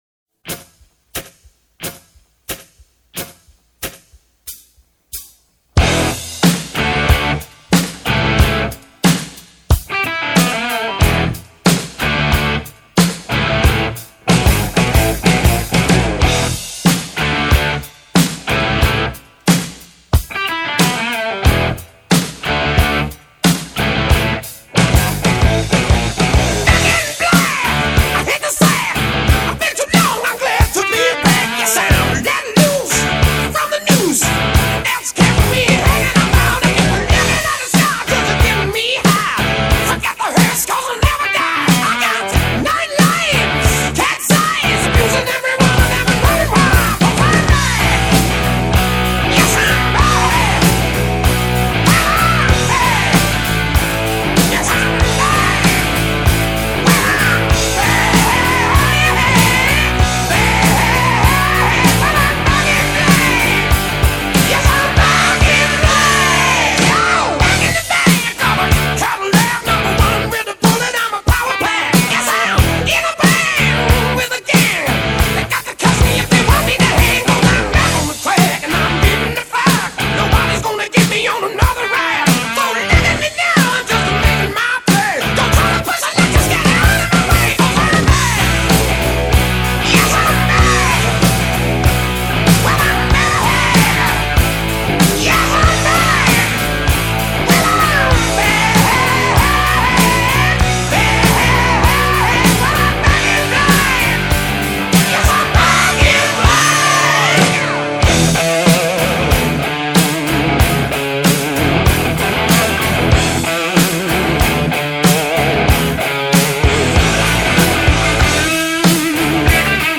2025-02-09 22:40:34 Gênero: Rock Views